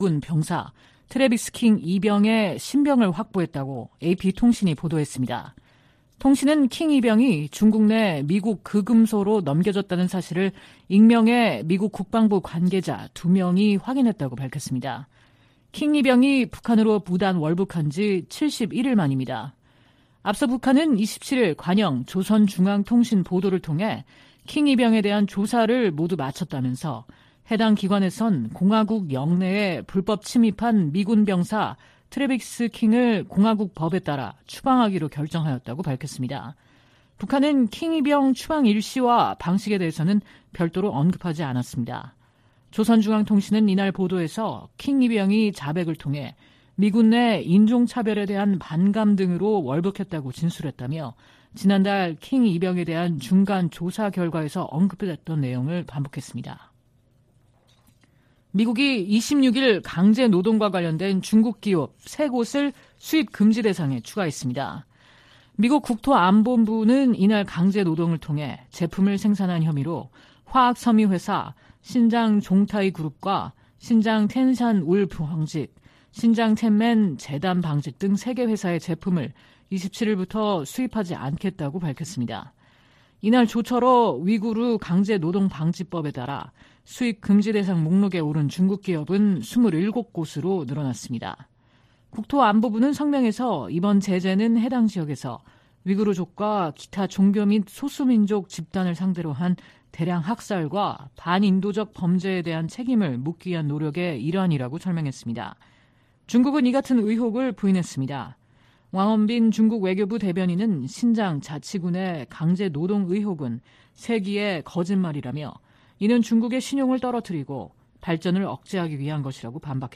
VOA 한국어 '출발 뉴스 쇼', 2023년 9월 28일 방송입니다. 미 국무부는 미한 연합훈련을 '침략적 성격이 강한 위협'이라고 규정한 북한 유엔대사의 발언에 이 훈련은 관례적이고 방어적인 것이라고 반박했습니다. 한국의 신원식 국방부 장관 후보자는 9.19 남북 군사합의 효력을 최대한 빨리 정지하도록 추진하겠다고 밝혔습니다. 미국과 한국, 일본 등은 제 54차 유엔 인권이사회에서 북한의 심각한 인권 유린 실태를 강력하게 규탄했습니다.